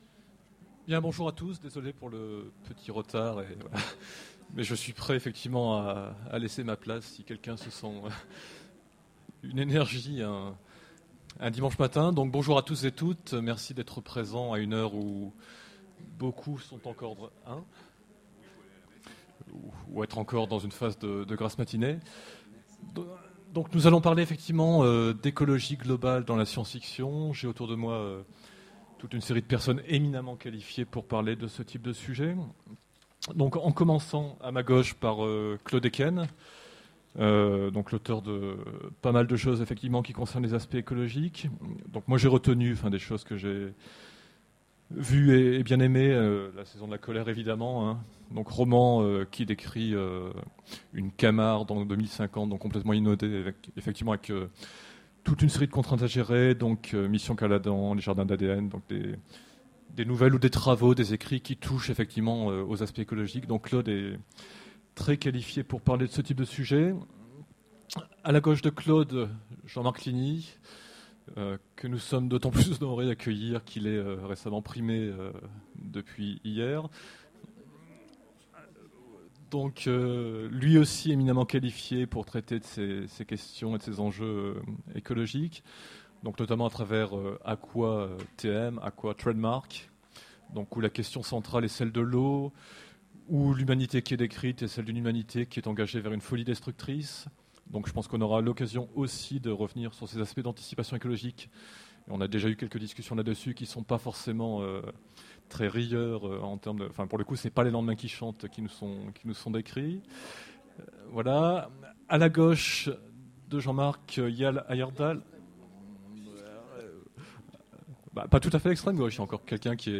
Utopiales 13 : Conférence L'écologie globale dans la science-fiction